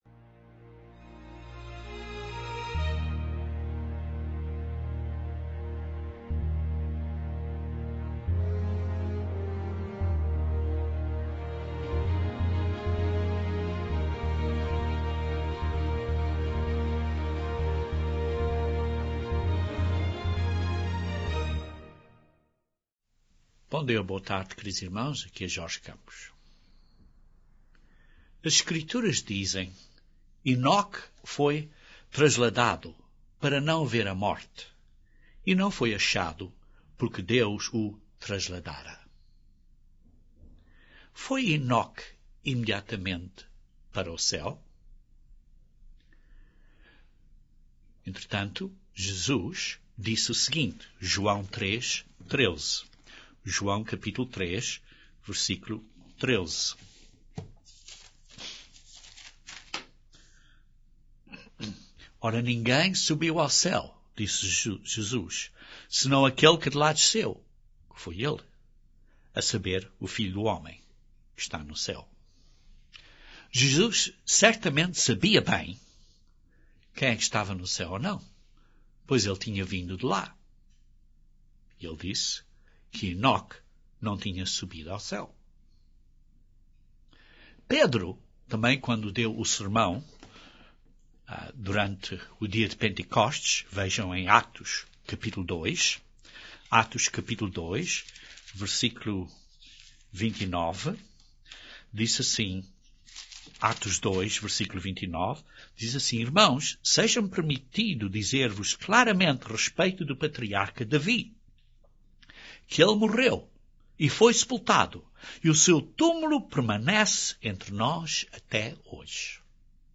Este sermão demonstra de sua própria Bíblia aonde está Enoque - e explica uns pontos muito interessantes acerca de Enoque que talvez você nunca tenha visto na Bíblia!